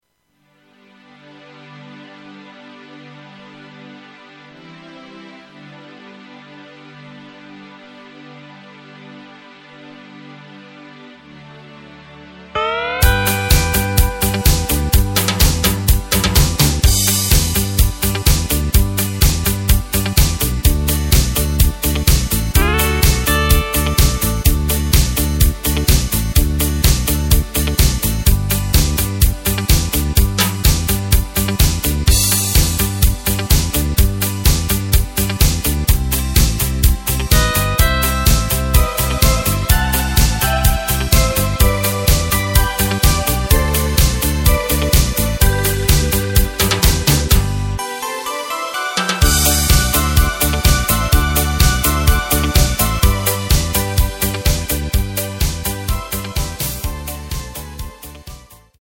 Takt:          4/4
Tempo:         126.00
Tonart:            G
Schlager aus dem Jahr 1985!
Playback mp3 Demo